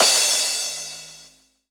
VTS1 Space Of Time Kit Drums & Perc
VTS1 Space Of Time Kit 140BPM Crash DRY.wav